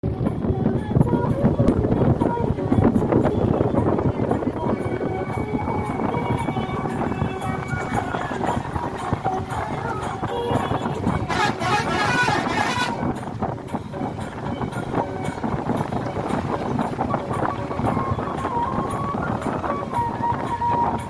Le reste du temps, le rabatteur, agrippé à une barre de fer, s’incline au-dessus de la route en hélant ‘Shivalaya Jiri Jiri Jiri Jiri Jiri’ en rythme, ce qui correspond aux destinations du bus.
C’est parti pour 7 heures de route rythmée de musique népalaise à tue-tête, de klaxons à chaque virage et du bruit de paume frappant contre la portière.